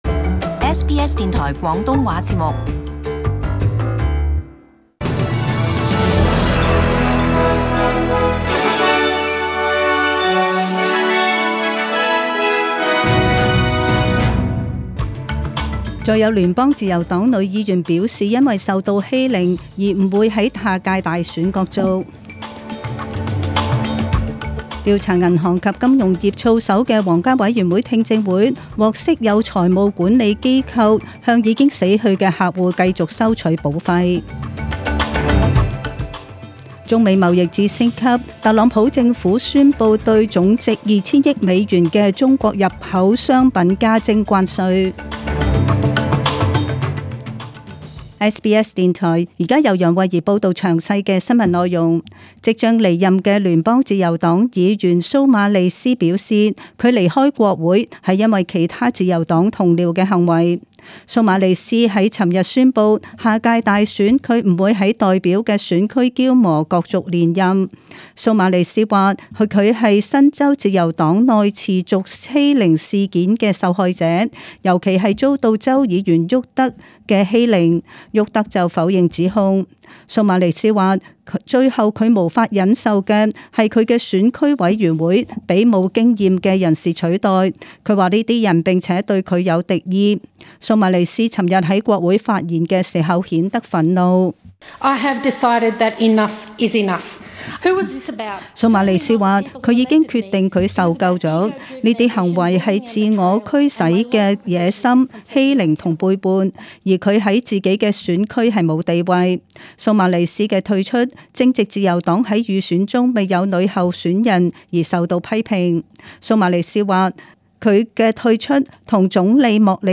请收听本台为大家准备的详尽早晨新闻。